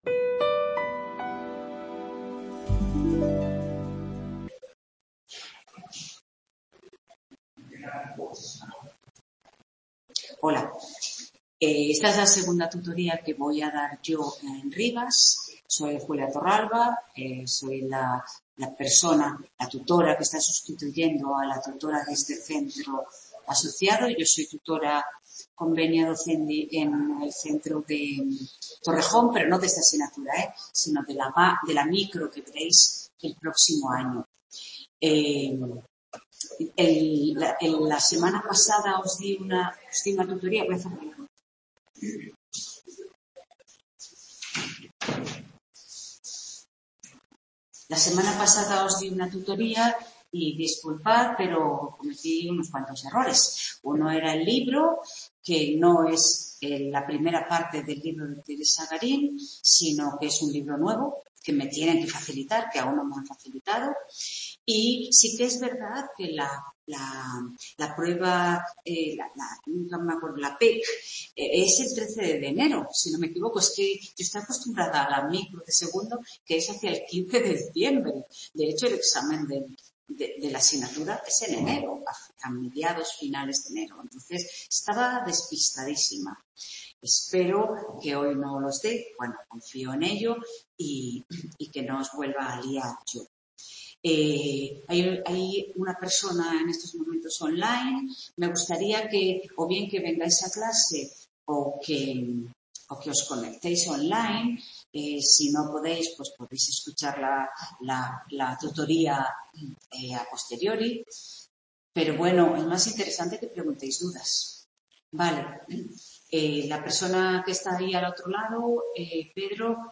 Tutoría de Introducción a la Micoeconomía 1º ADE de 19… | Repositorio Digital